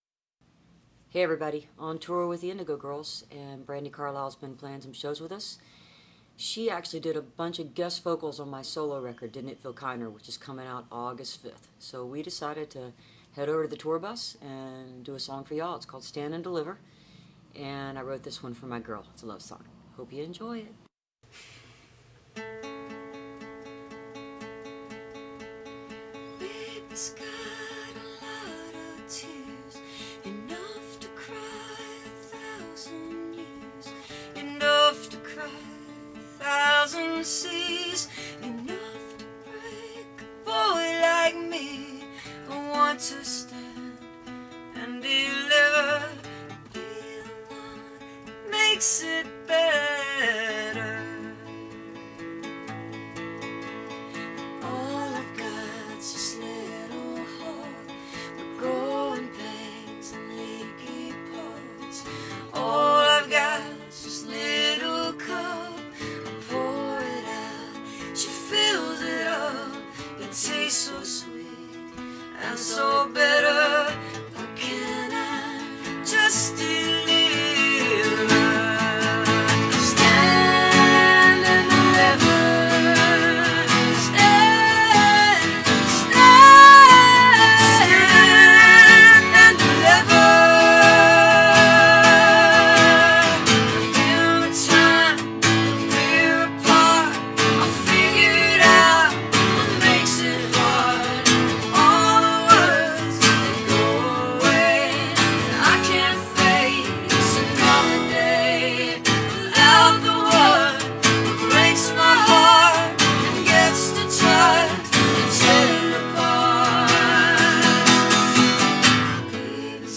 2008. all the performances are acoustic